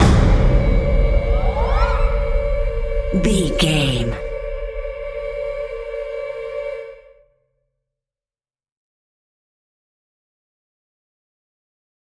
Sound Effects
Atonal
ominous
dark
haunting
eerie
drums
percussion
vocals